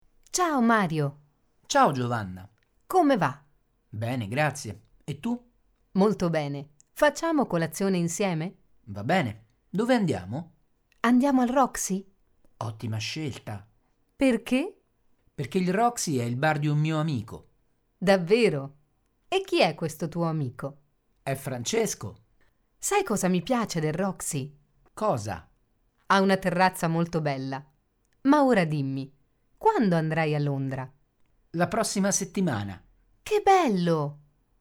dialogo su chi, cosa, dove, come, quando, perché?